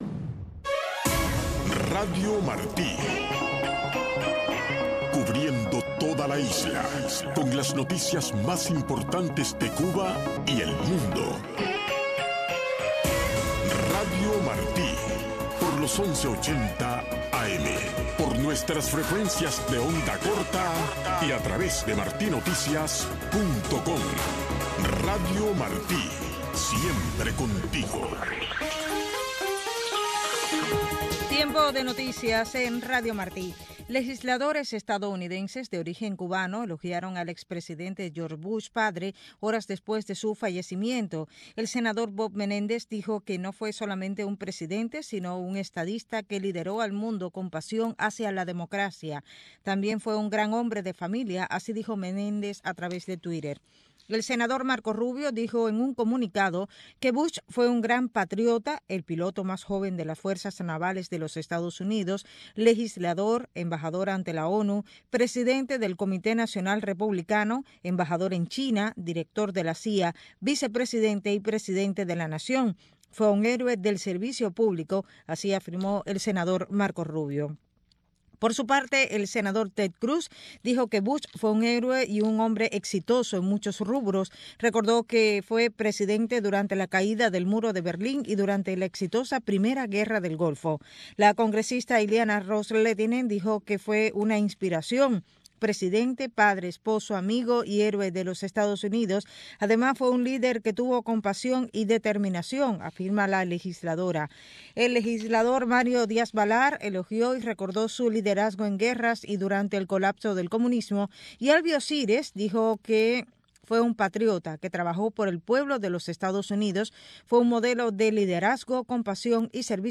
Moderador Tomas Regalado. Invitado: Carlos Alberto Montaner.